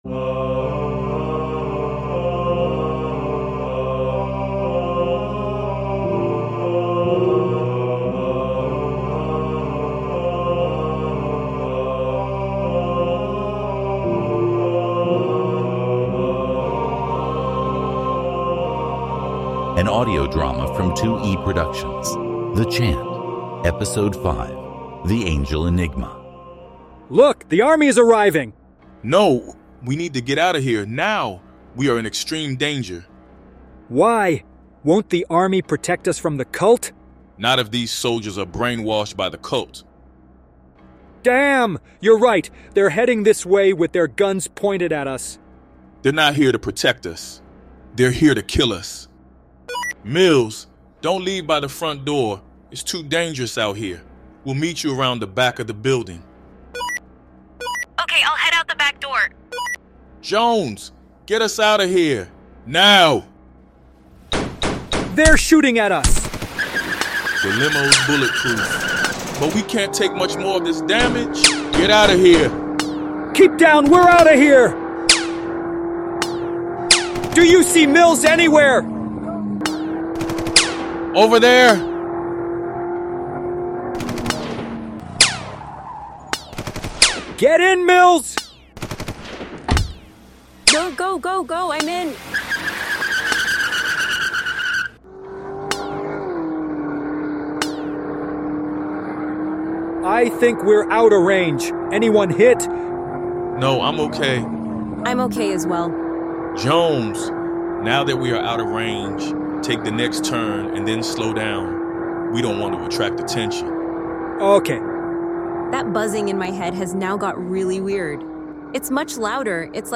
Audio Drama 2e Production Content provided by 2e Production.